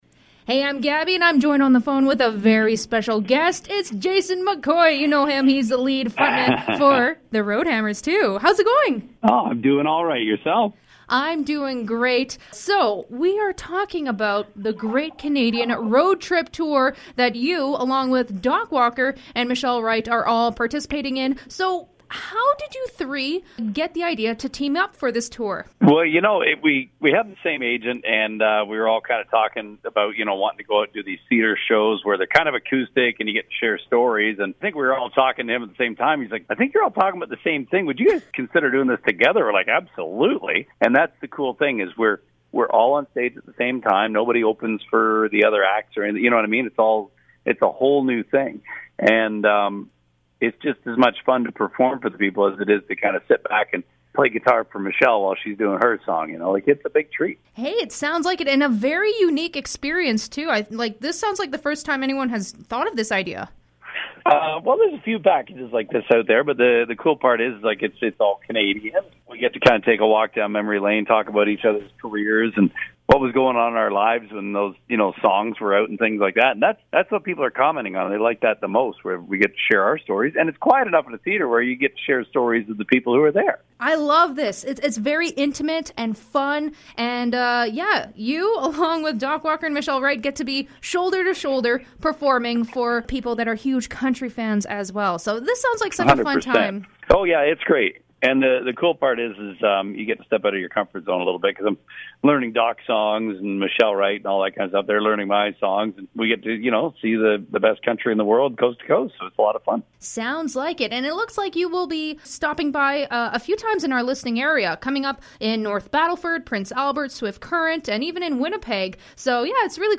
Jason McCoy interview